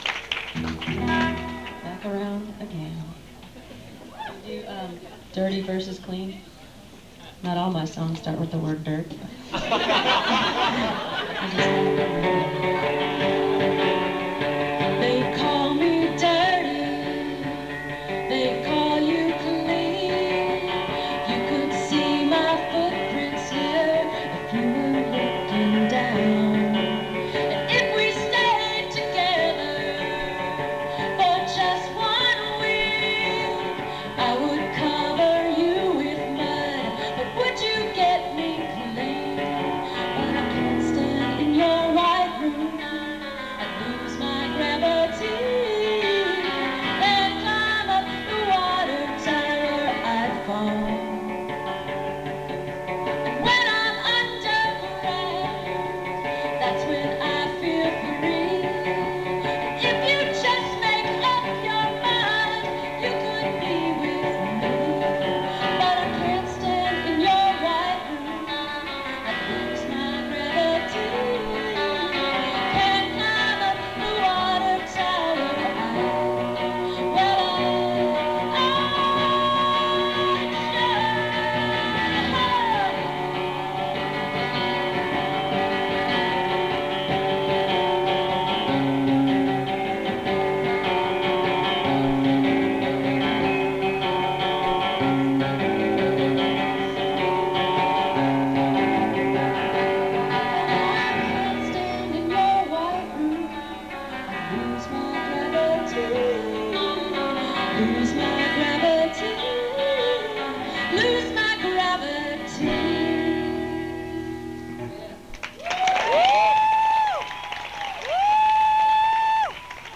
(acoustic show)